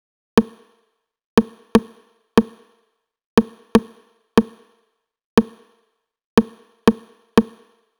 Ew Clicks.wav